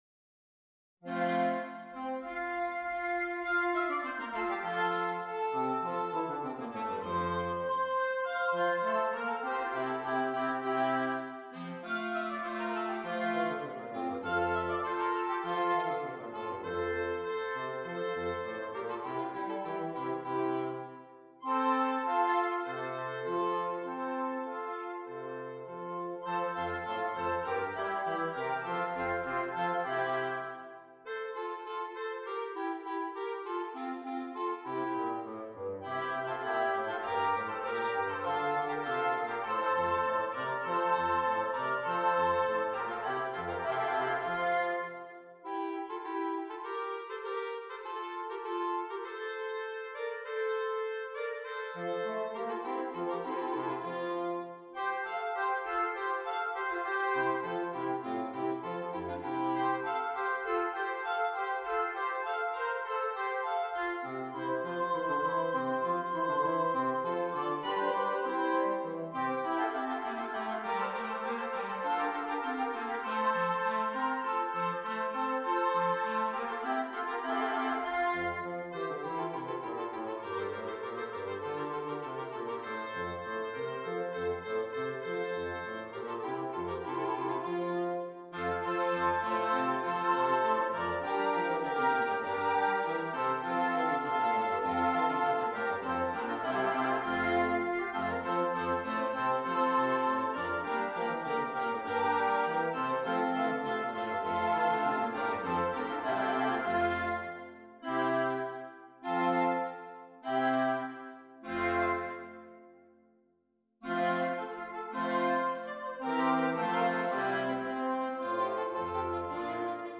Interchangeable Woodwind Ensemble